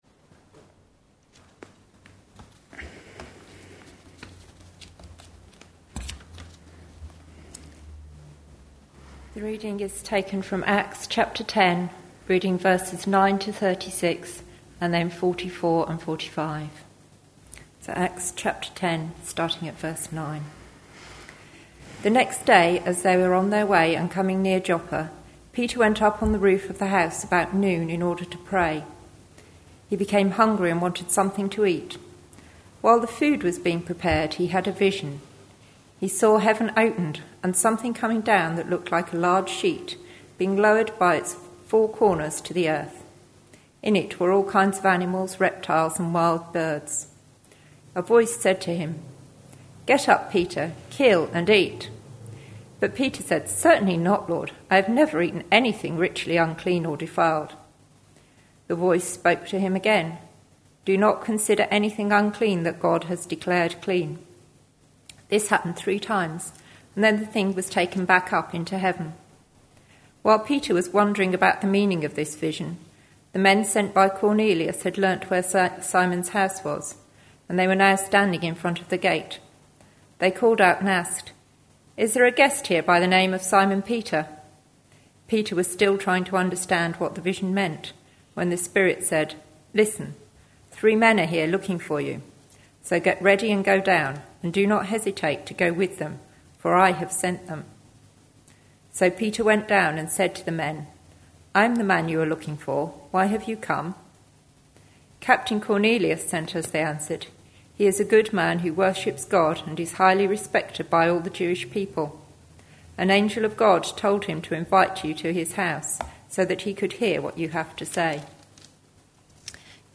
A sermon preached on 25th May, 2014, as part of our Connecting With Culture series.